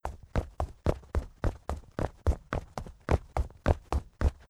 Steps.wav